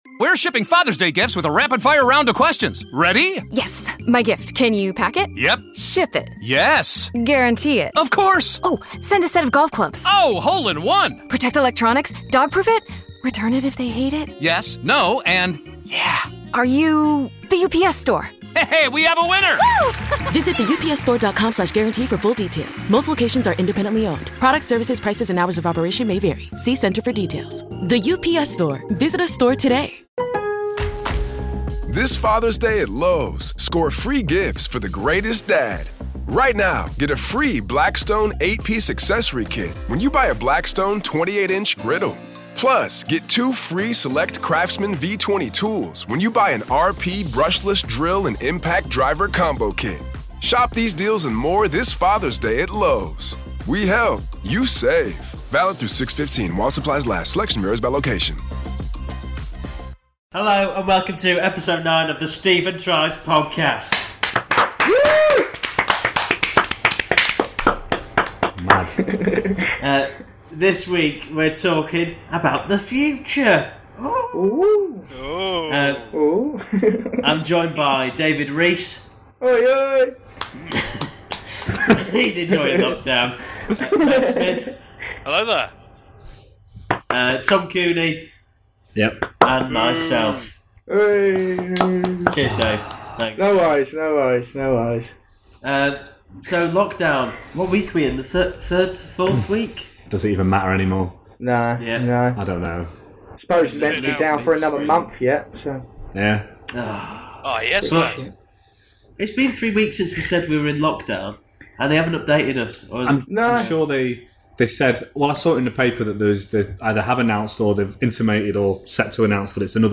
Comedy Interviews, Comedy